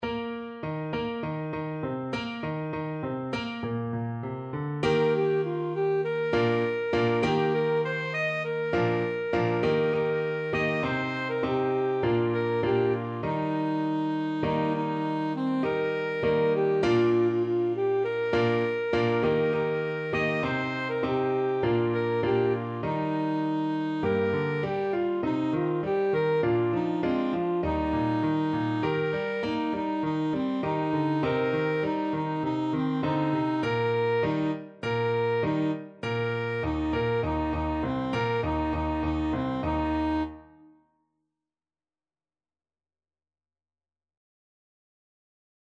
Alto Saxophone version
World Asia China
4/4 (View more 4/4 Music)
Joyfully =c.100
Traditional (View more Traditional Saxophone Music)